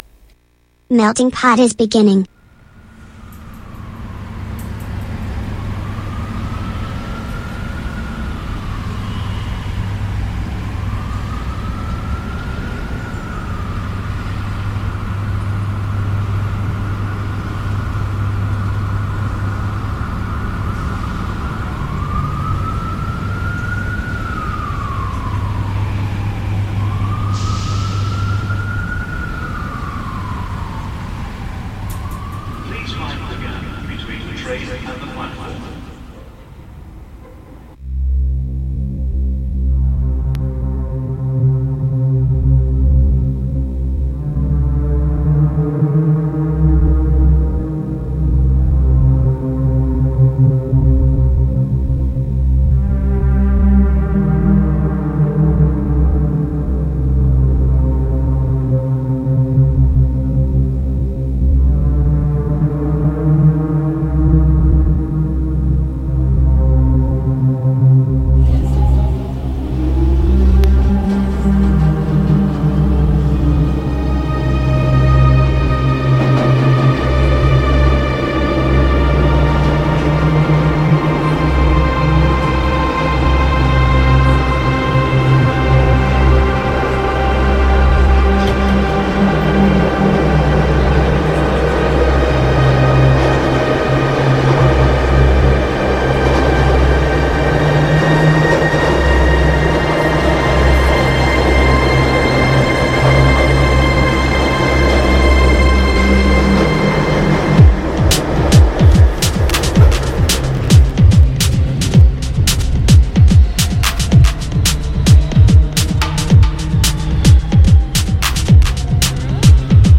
Clip tratta dal film I Guerrieri della Notte
Intervista